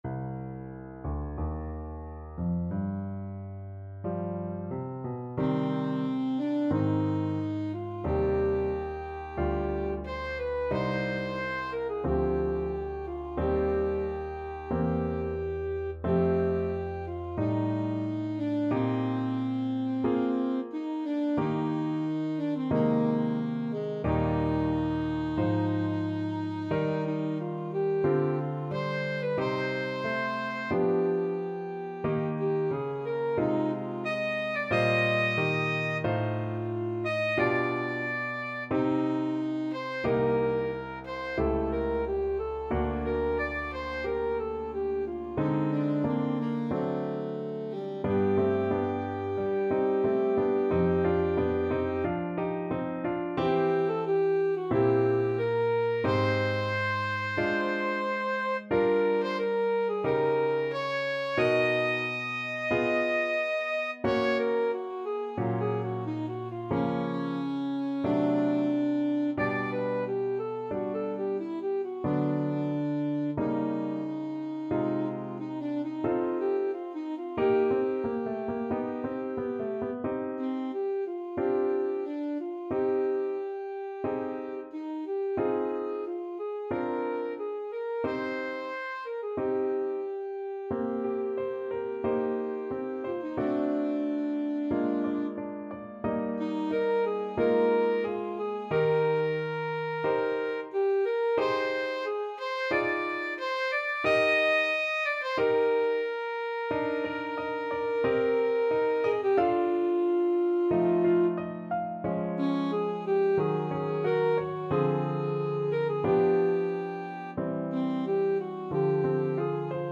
Classical Faure, Gabriel Piece (Vocalise-Etude) Alto Saxophone version
Alto Saxophone
C minor (Sounding Pitch) A minor (Alto Saxophone in Eb) (View more C minor Music for Saxophone )
4/4 (View more 4/4 Music)
Adagio, molto tranquillo (=60) =45
G4-Eb6
Classical (View more Classical Saxophone Music)